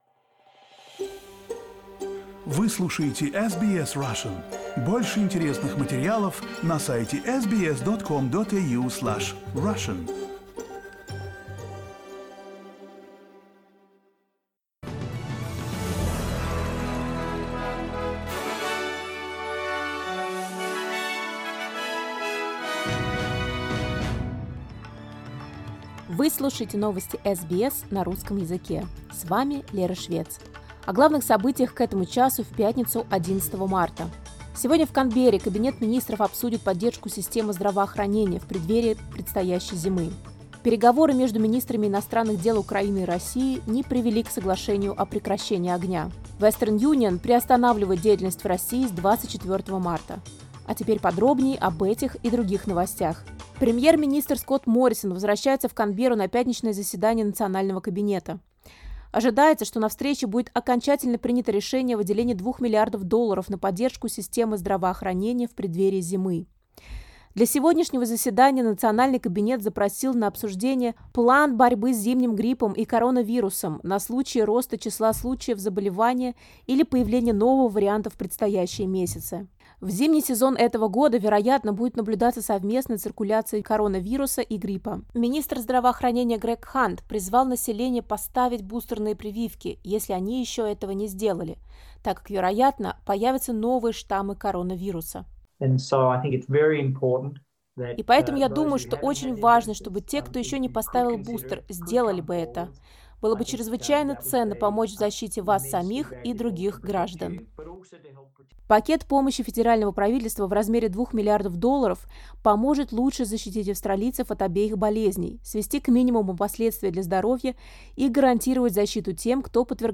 Listen to the latest news headlines in Australia from SBS Russian